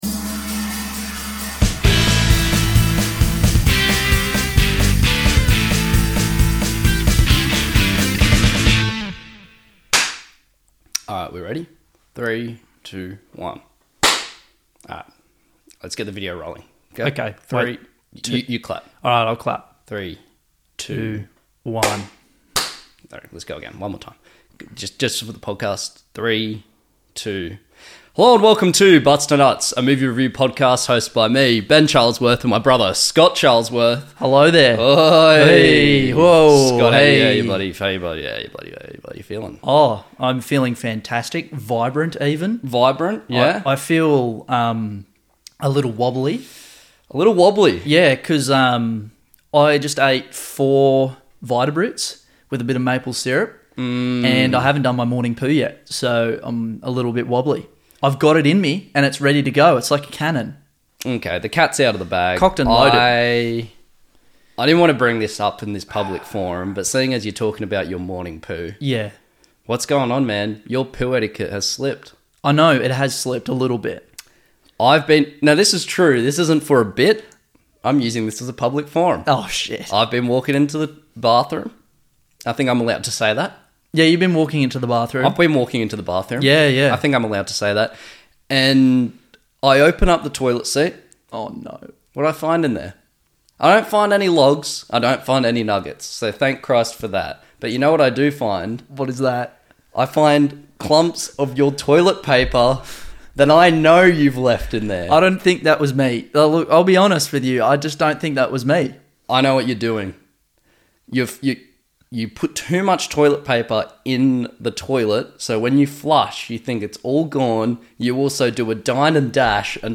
This week the boys reviewed Goodfellas! This episode includes sliced garlic, Italian accents and toilet talk (of course).